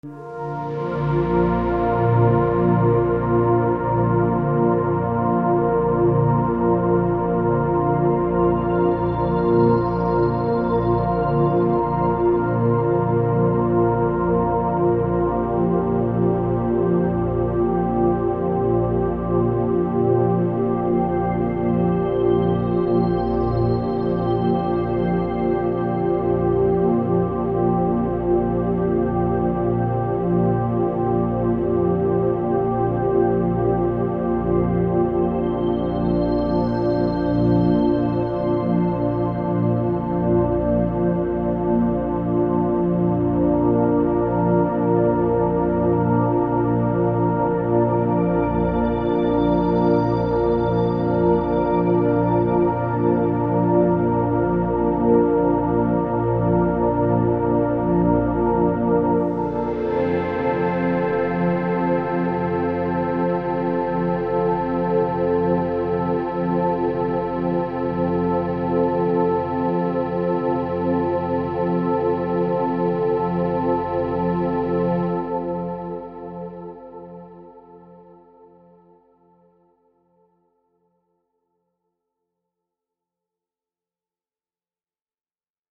Wind Down into Delta (Binaural Beats 2Hz)
This 70 minute track was created using 2Hz binaural beats. Designed to be listened to with headphones, these tones can help you relax deeply in meditation or wind down into a restful sleep.
2hz-binaural-beats-preview.mp3